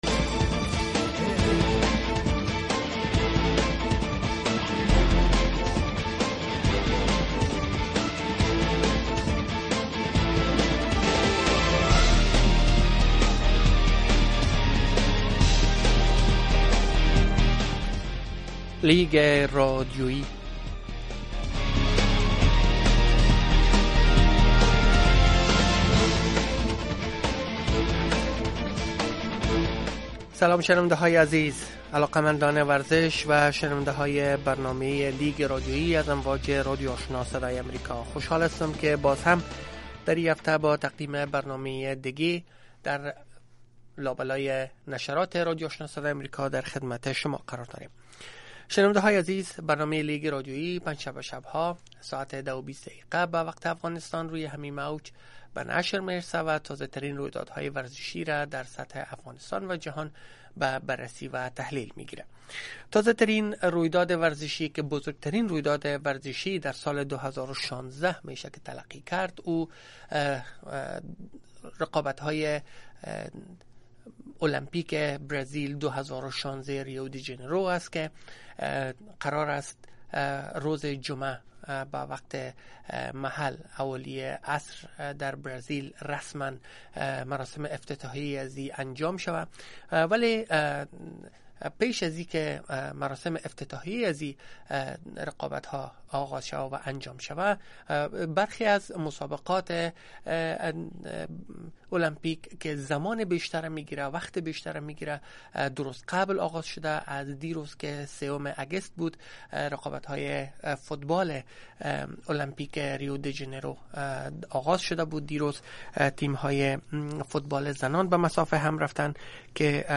صحبت